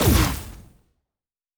Sci Fi Explosion 15.wav